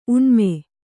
♪ uṇme